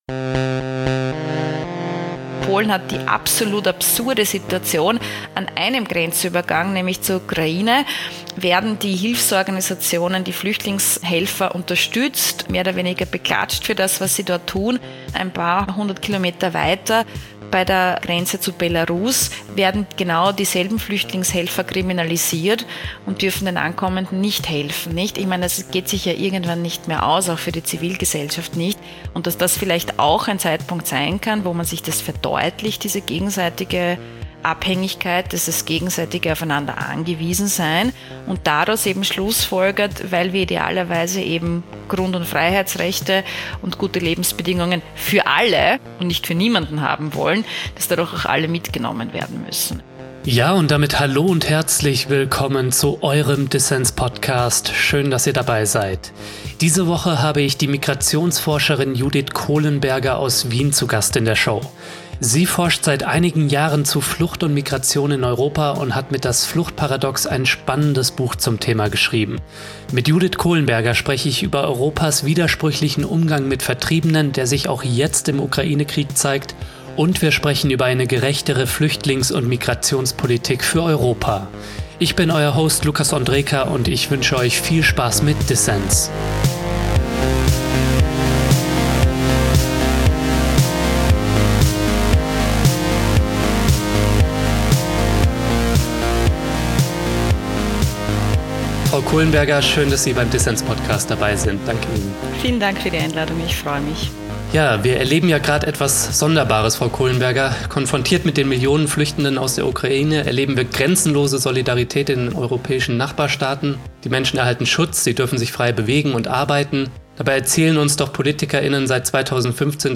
Ein Gespräch über Geflüchtete zweiter Klasse, legale Fluchtwege für alle und ein Europa der Freizügigkeit.